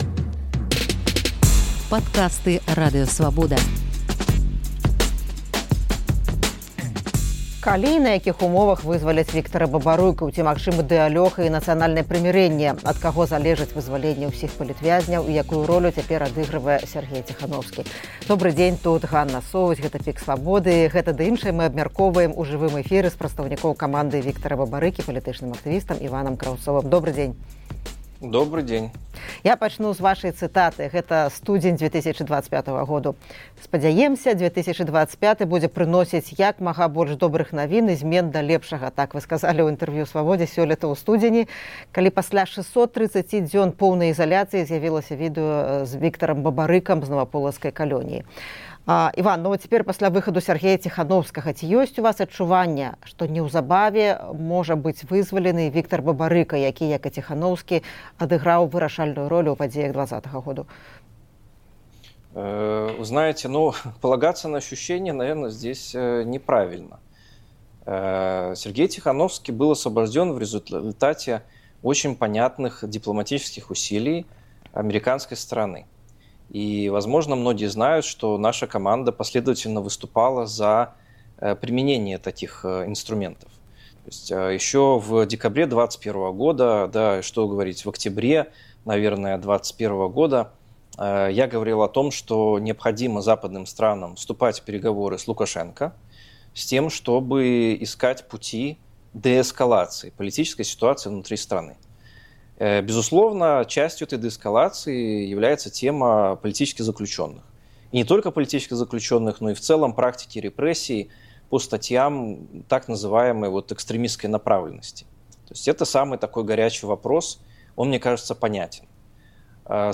Гэта ды іншае ў жывым эфіры «ПіКу Свабоды»